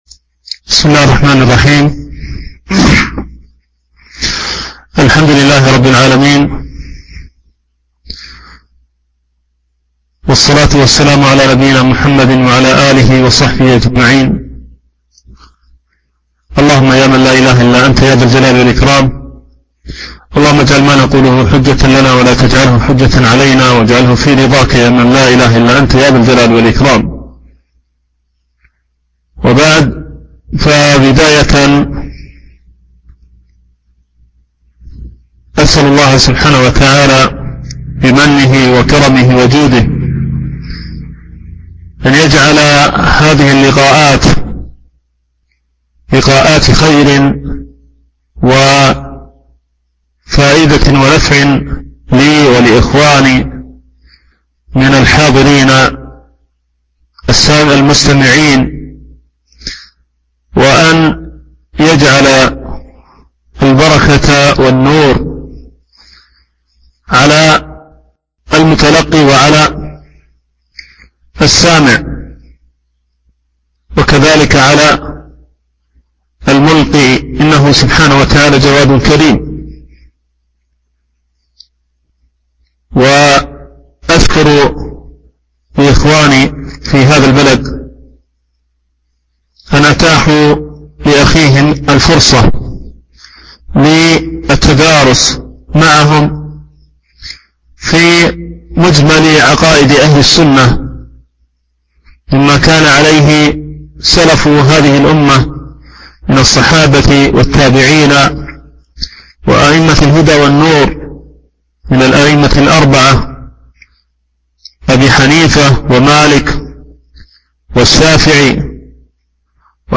شرح مقدمة رسالة ابن أبي زيد القيرواني - الدرس الأول